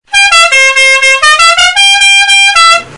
автомобильный клаксон
Автомобильный клаксон. Очень громкий.